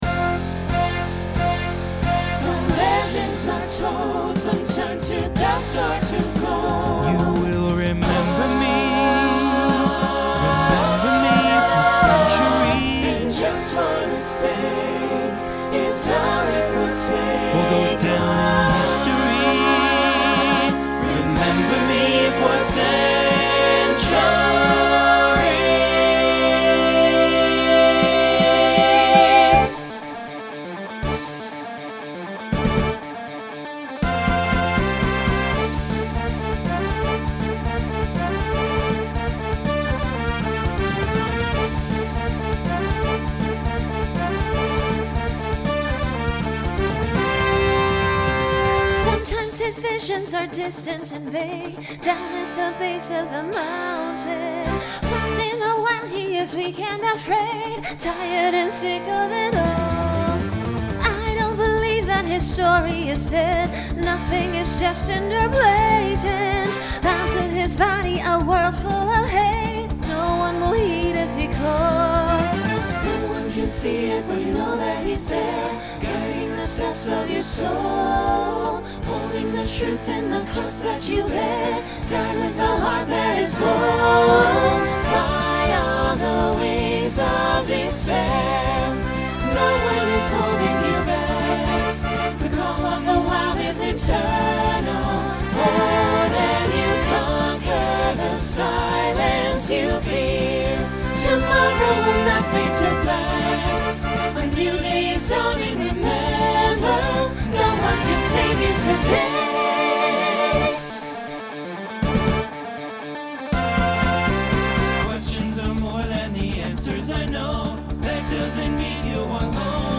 Genre Rock
Voicing SATB